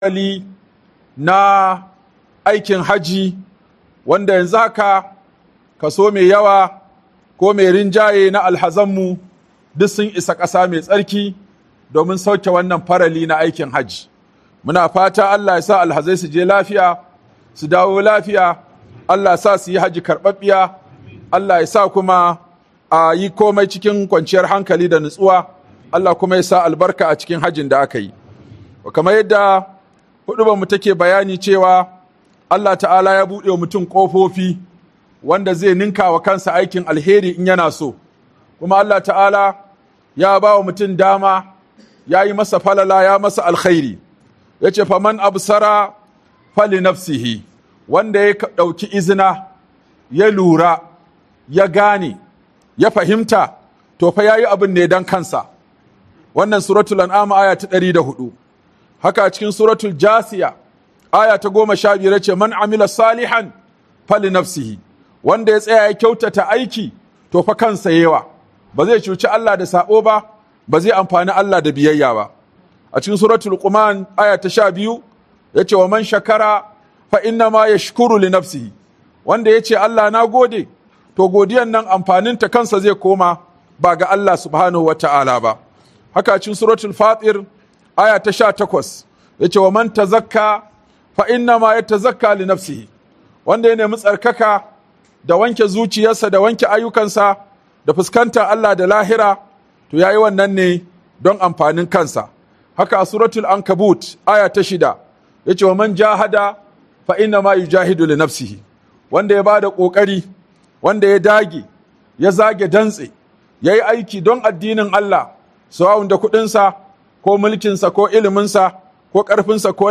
Kowa Yayi Aiki Nagari Dan Kansa - Huduba by Sheikh Aminu Ibrahim Daurawa